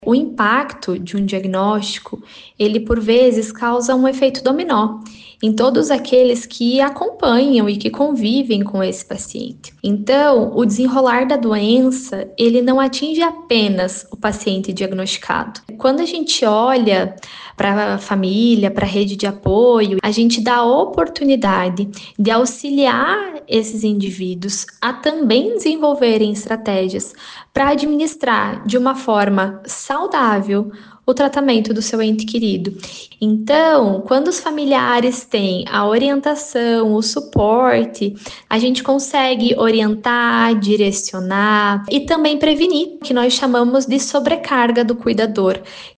Nesses casos, a recomendação é para que o acompanhamento psicológico também se estenda para os familiares mais próximos. Segundo a psicóloga, é preciso ajudar os entes a se tornarem uma fonte de apoio ainda mais efetiva.